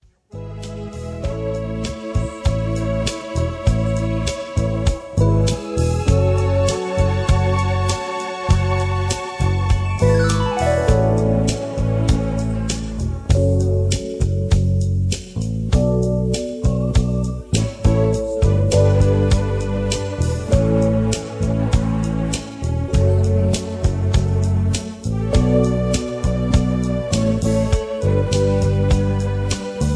Key-E) Karaoke MP3 Backing Tracks
Just Plain & Simply "GREAT MUSIC" (No Lyrics).